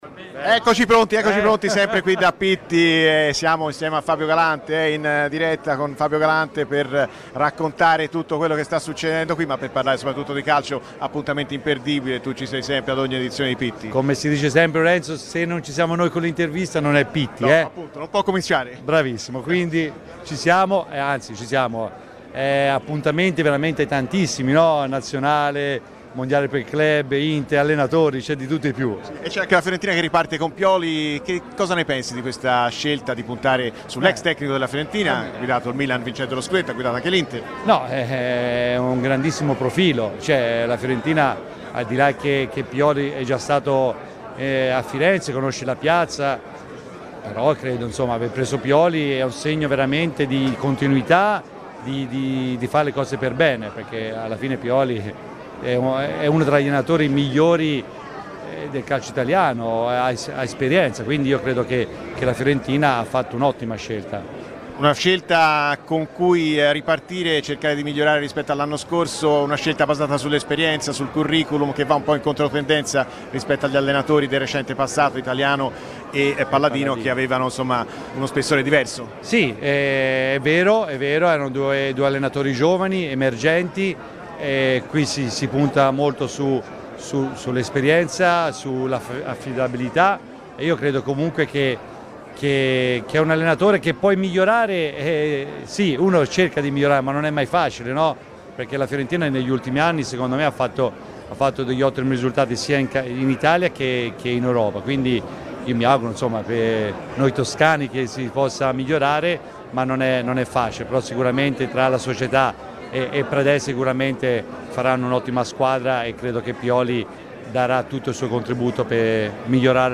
L'ex difensore Fabio Galante, presente a Pitti Uomo, evento di alta moda in corso di svolgimento a Firenze, ha parlato a Radio FirenzeViola, durante "Viola Amore Mio".